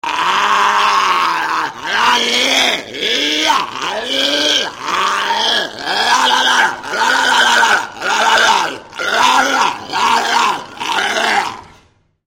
В подборке представлены характерные аудиозаписи: мощный рев и коммуникационные сигналы этих удивительных животных.
Звук моржа: крик